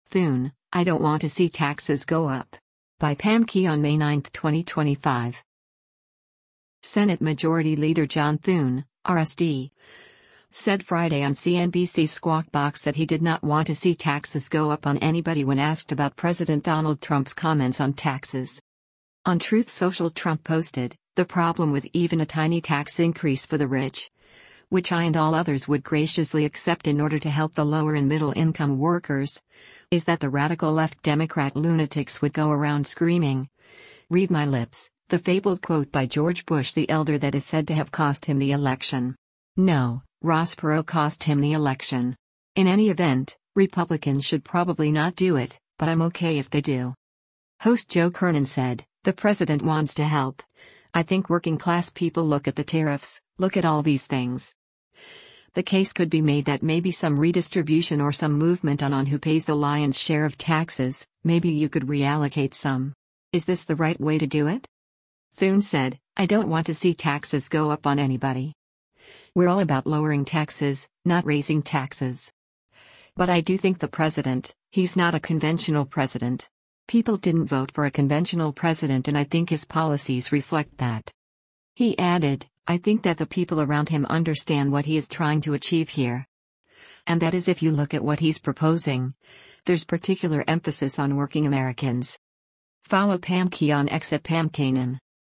Senate Majority Leader John Thune (R-SD) said Friday on CNBC’s “Squawk Box” that he did not want to see taxes go up on anybody when asked about President Donald Trump’s comments on taxes.